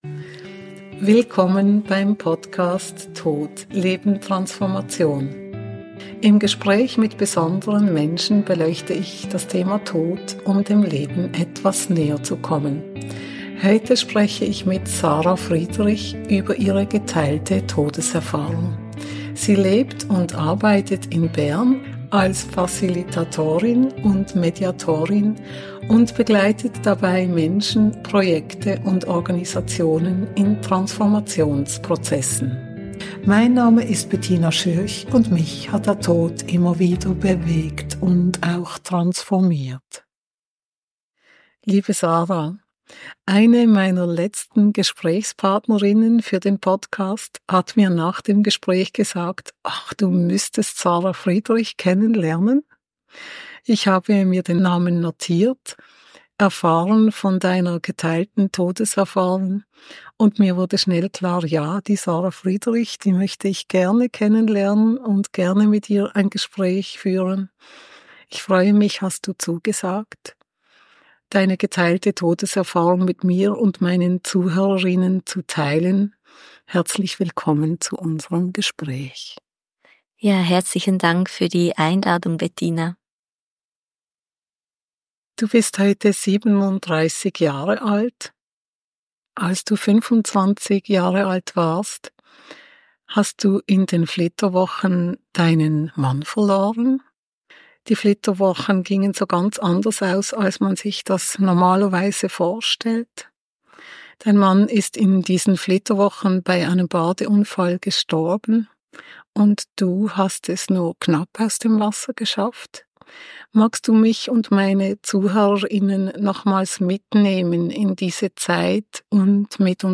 Im Gespräch teilt sie ihre Erfahrungen rund um den Verlust ihres Mannes.